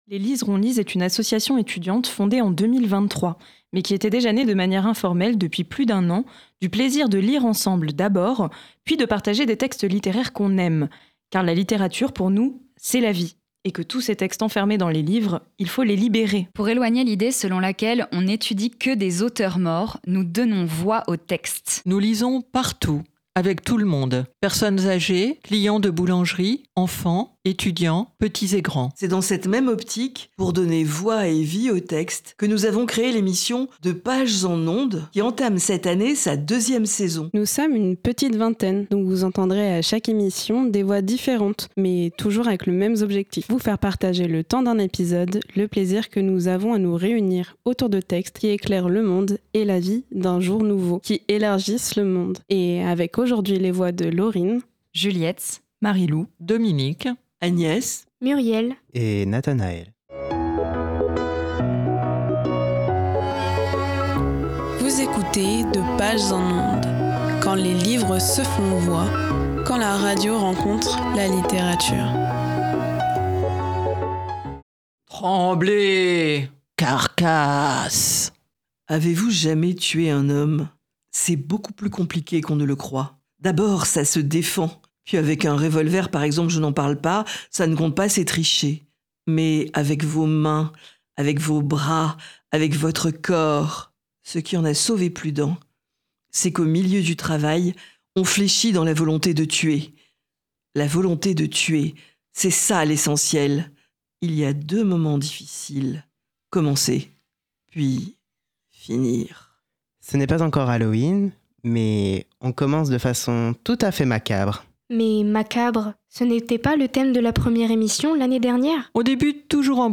Bienvenue dans De pages en Ondes, une émission littéraire où la littérature rencontre la radio.
Vous entendrez à chaque émission des voix différentes qui ont un objectif : vous faire partager, le temps d’un épisode, le plaisir que nous avons à nous réunir autour de textes qui éclairent le monde et la vie d’un jour nouveau, qui élargissent le monde !